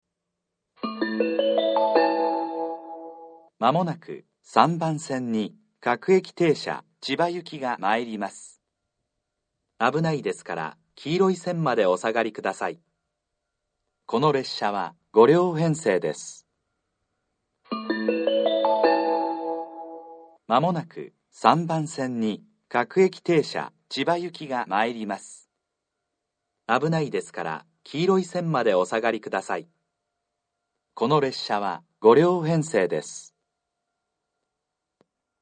３番線接近放送 各駅停車千葉行（５両）の放送です。